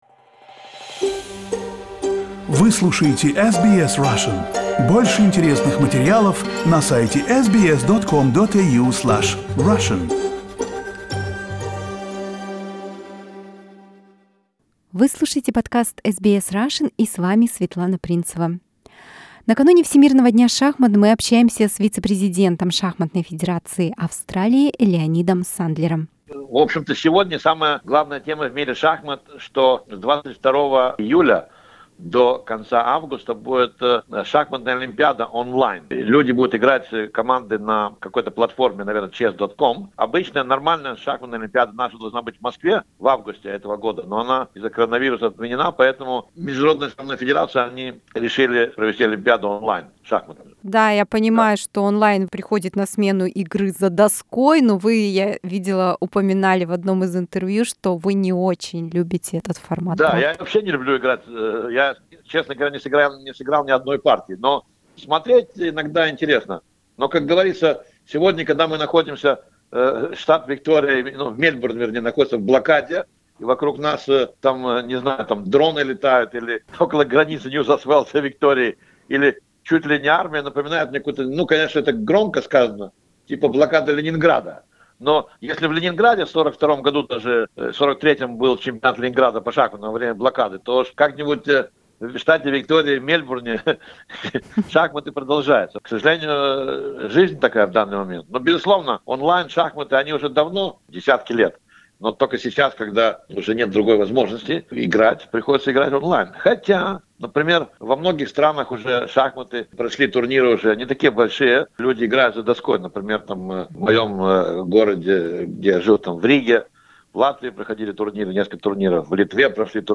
Когда мы записывали это интервью, то точных данных о составе австралийской команды для участия в Шахматной Олимпиаде еще не было.